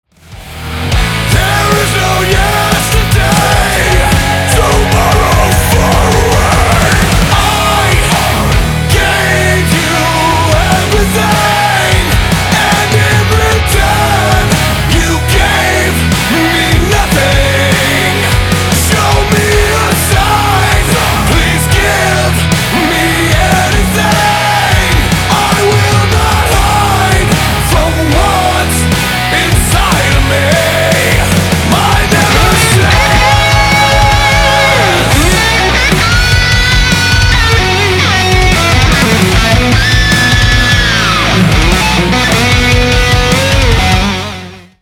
• Качество: 320, Stereo
громкие
мощные
брутальные
Alternative Metal
heavy Metal
groove metal